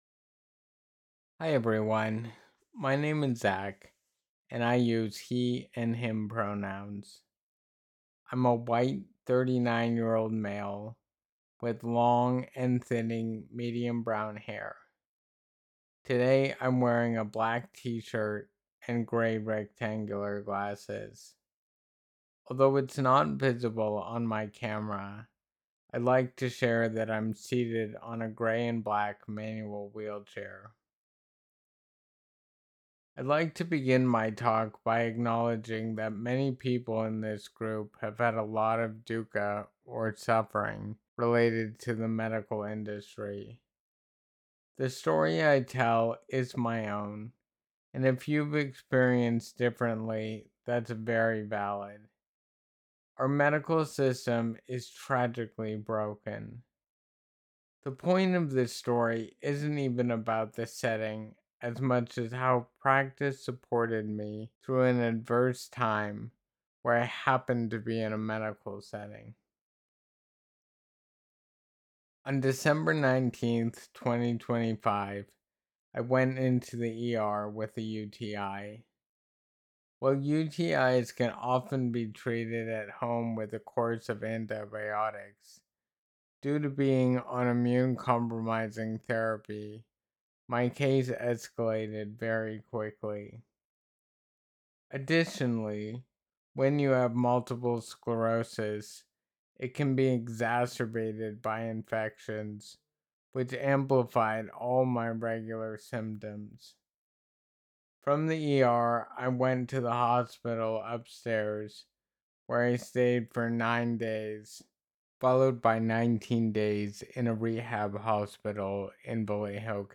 Please Note: The following recording was from a script of a talk given at EBEM, but was recorded at another time to preserve the confidentality of participants.